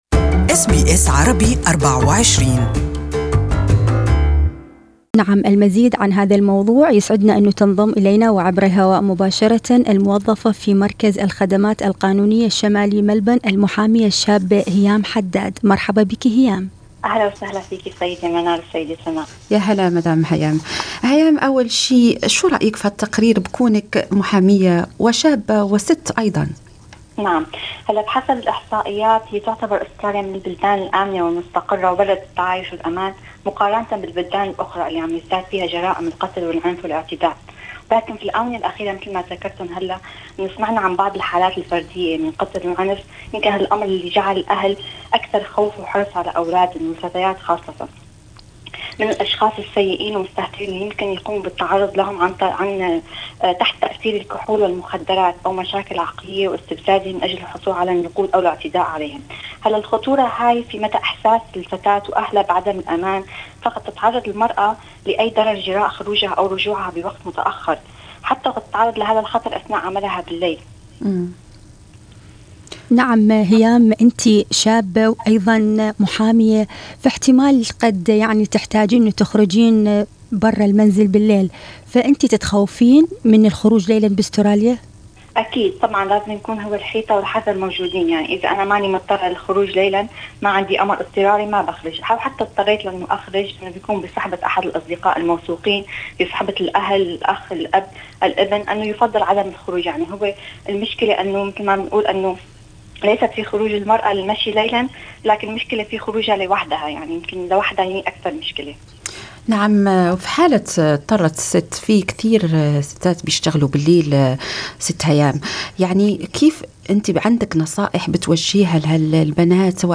This interview is only available in Arabic